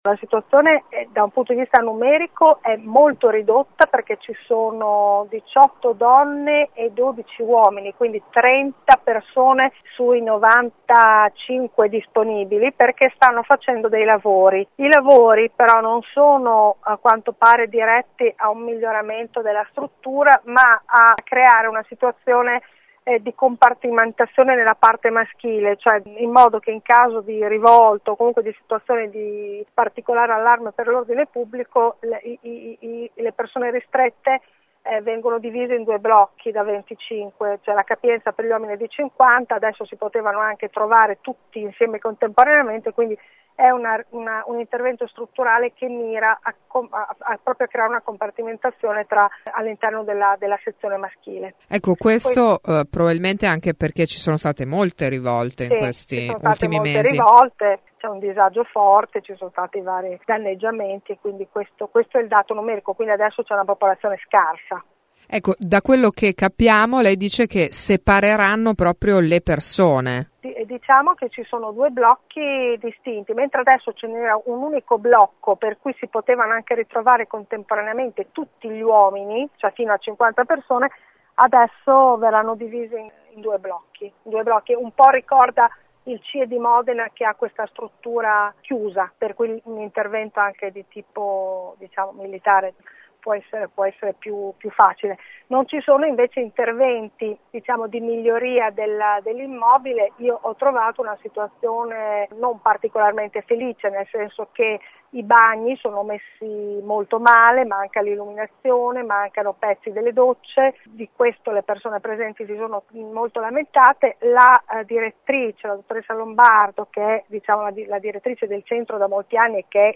20 set. – “Non è un belvedere” ha detto ai nostri microfoni l’avvocato Desi Bruno dopo aver visitato il Centro di identificazione ed espulsione di Bologna.